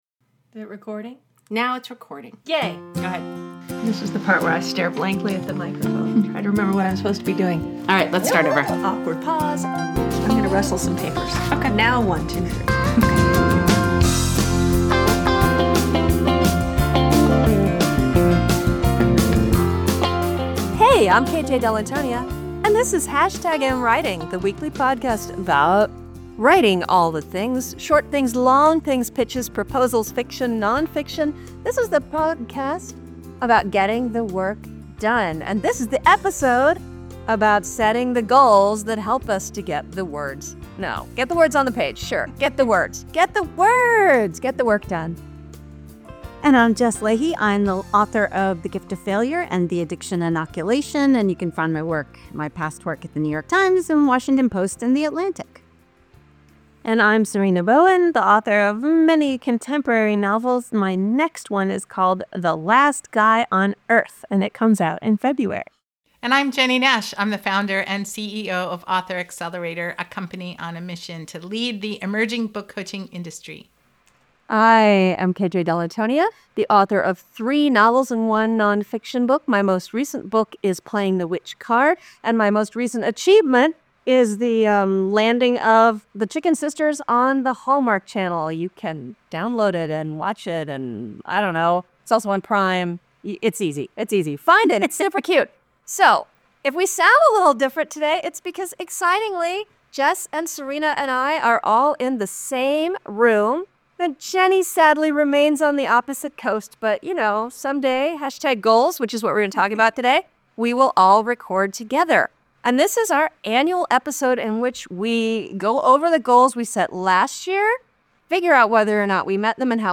(And the audio is also less great, because 3 of us gathered in our local library and the acoustics/HVAC system noise were less than ideal.)